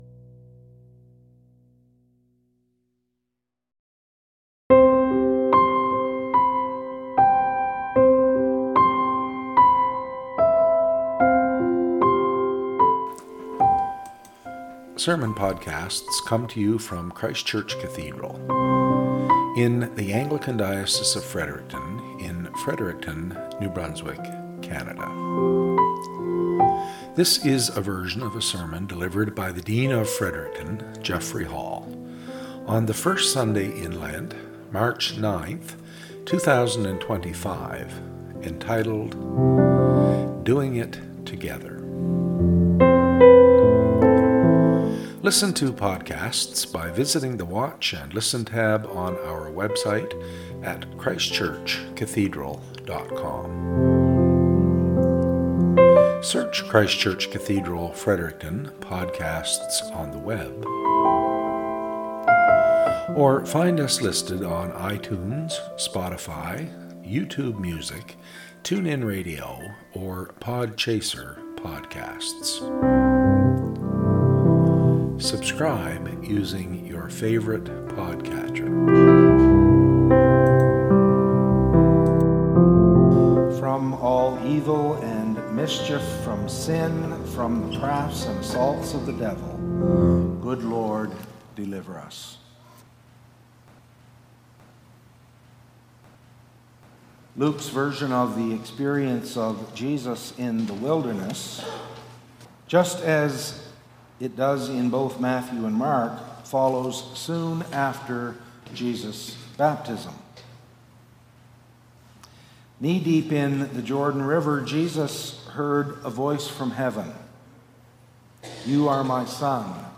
SERMON - "Doing it Together"